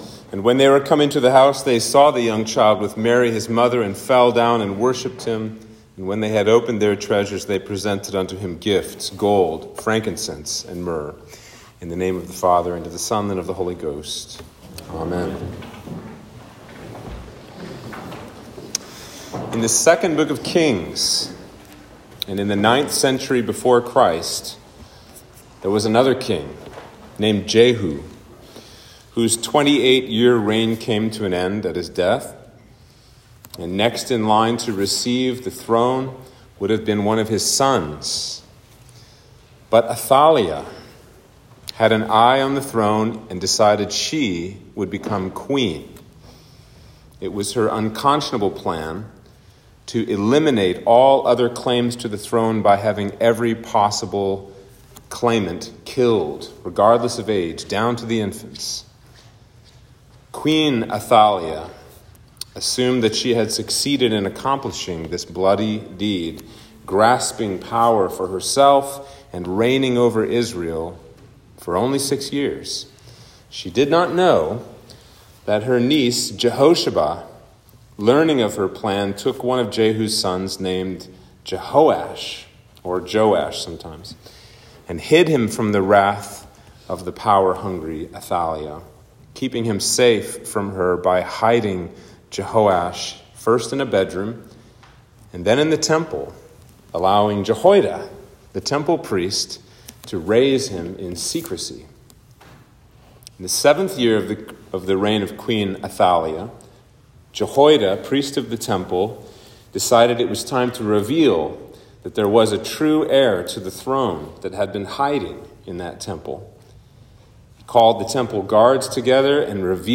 Sermon for Epiphany